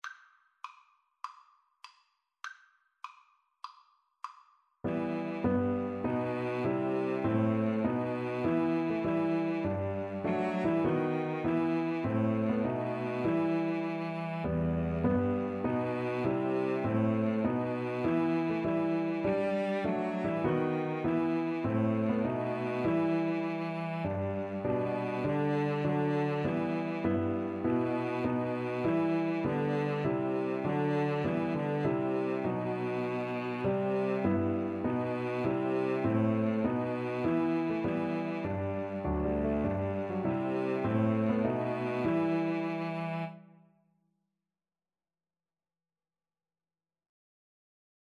4/4 (View more 4/4 Music)
Violin-Cello Duet  (View more Easy Violin-Cello Duet Music)
Classical (View more Classical Violin-Cello Duet Music)